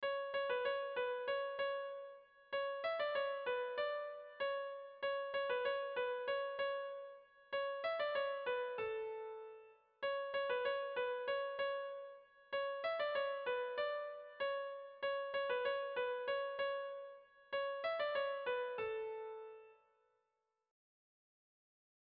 Dantzakoa
Zortziko txikia (hg) / Lau puntuko txikia (ip)
A1A2A1A2